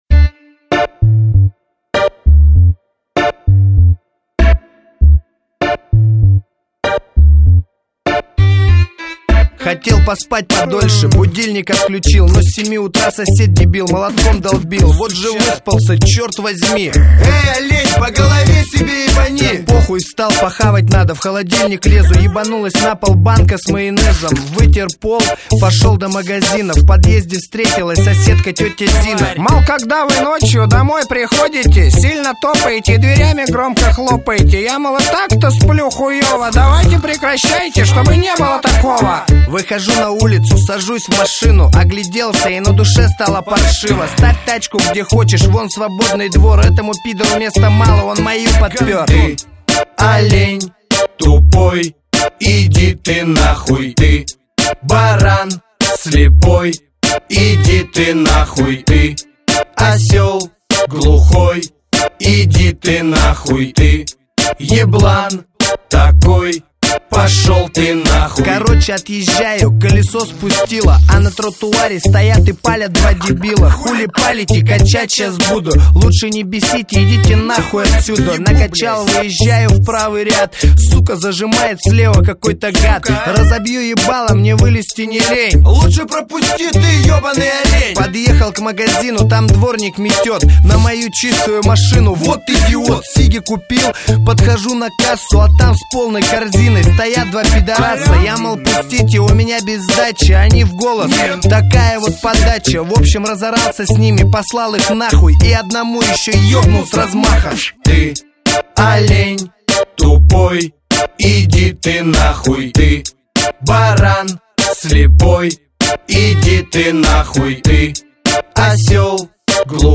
Жанр: Рэп